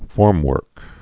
(fôrmwûrk)